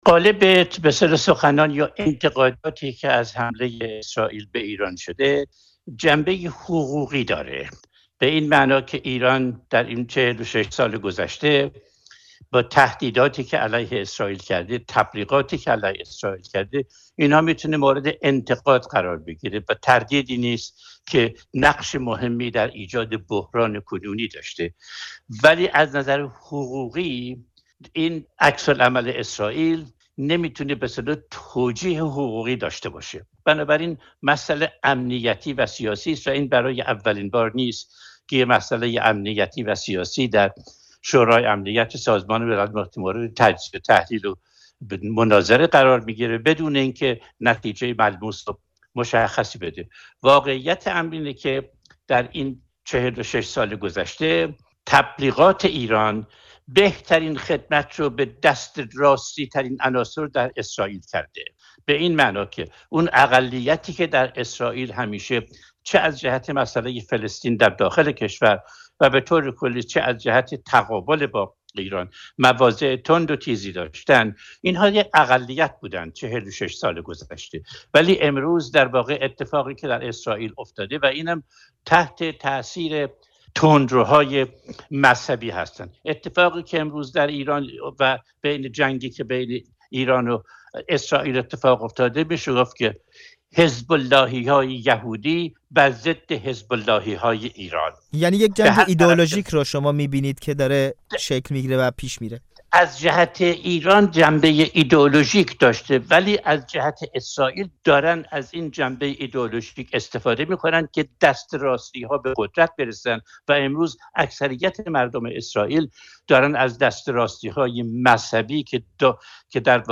منصور فرهنگ اولین سفیر ایران در سازمان ملل بعد از انقلاب به رادیوفردا می‌گوید، غالب انتقادها به درگیری اسرائیل با ایران حقوقی بوده، حال آن که این موضوعی است امنیتی و سیاسی .